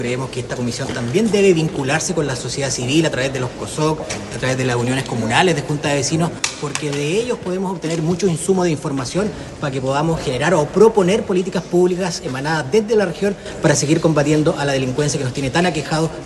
El consejero César Negrón añadió que para fortalecer la seguridad pública, se deben escuchar las preocupaciones de las juntas de vecinos.
comision-seguridad-gore-los-lagos.mp3